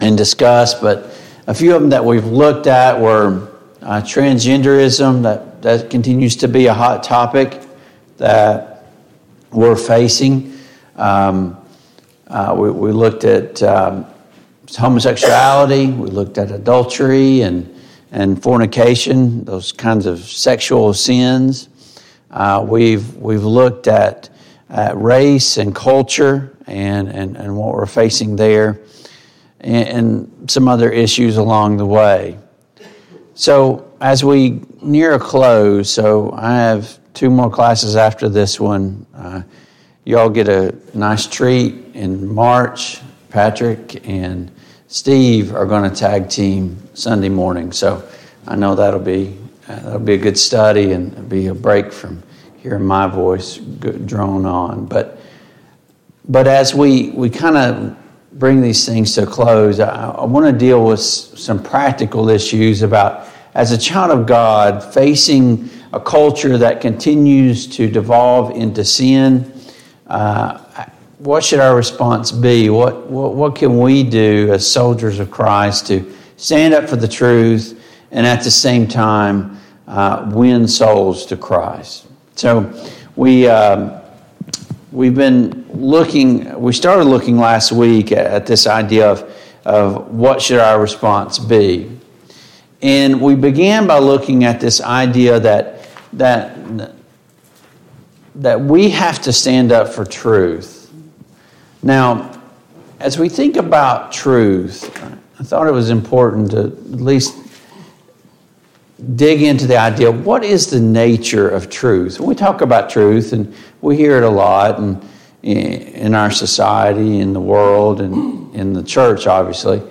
Deuteronomy 6:4-9 Service Type: Sunday Morning Bible Class « 65.